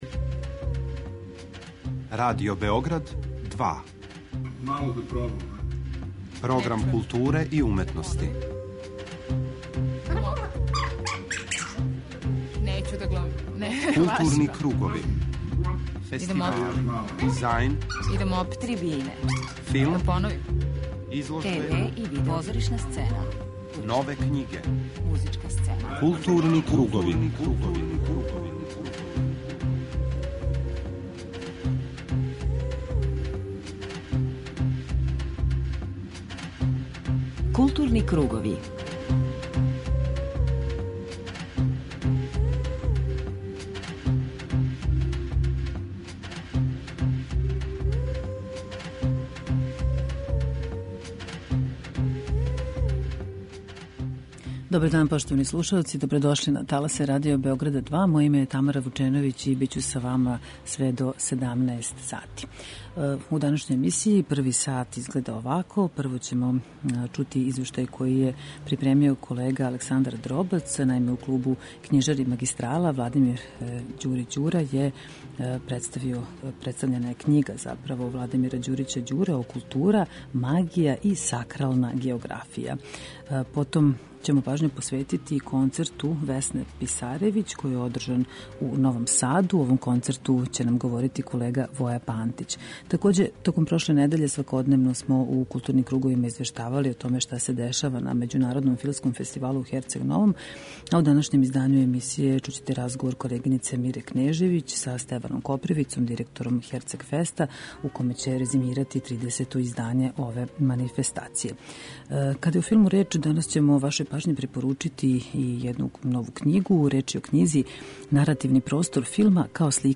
преузми : 38.55 MB Културни кругови Autor: Група аутора Централна културно-уметничка емисија Радио Београда 2.